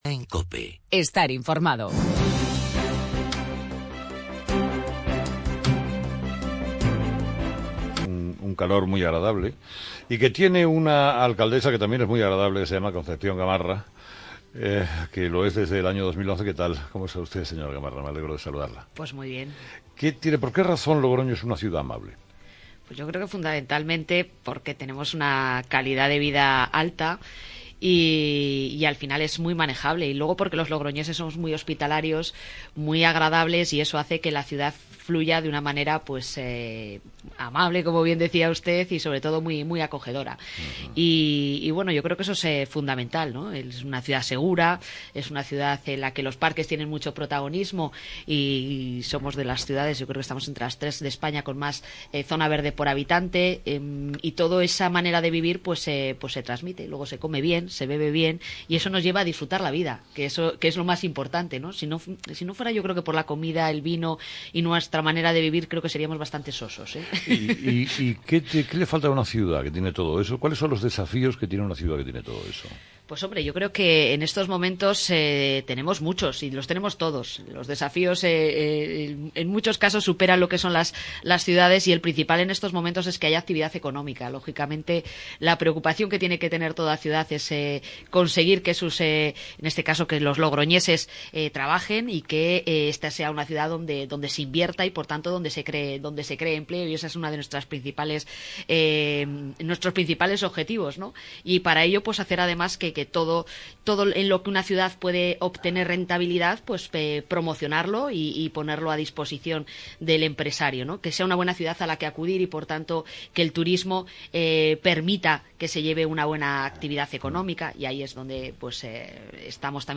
Entrevista a Concepción Gamarra
Entrevista a Condepción Gamarra, alcaldesa de Logroño.